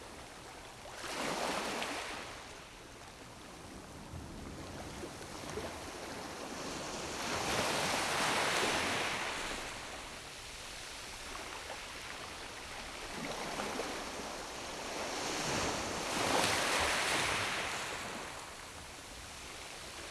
sea_shore_ambiX.wav